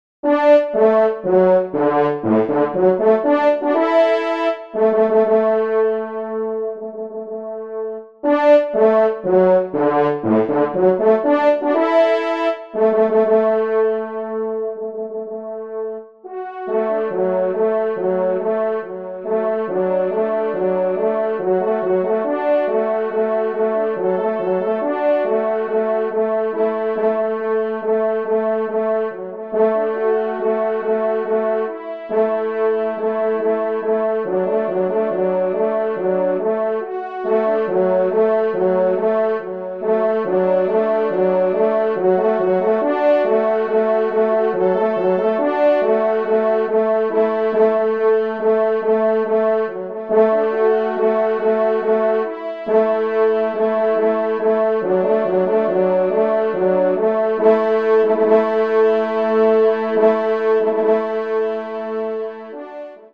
2ème Trompe